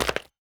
Chopping and Mining
mine 2.ogg